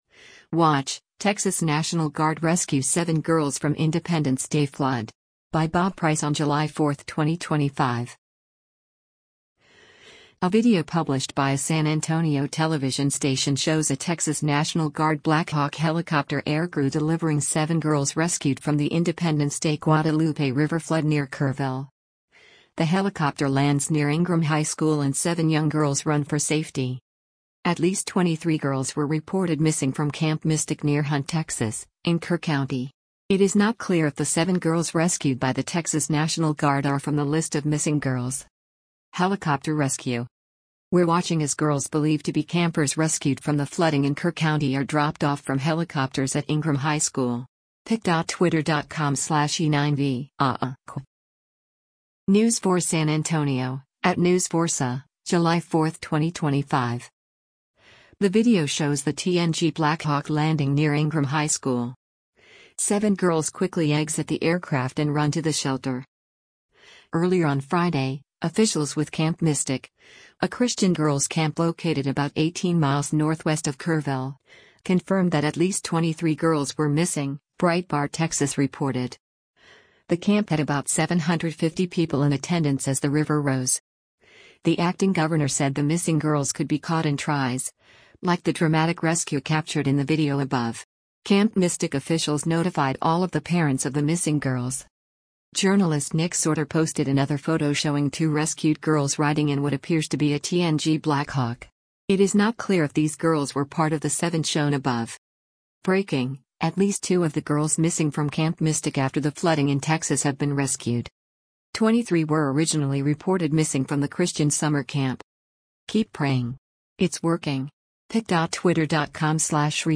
The helicopter lands near Ingram High School and seven young girls run for safety.
The video shows the TNG Blackhawk landing near Ingraham High School.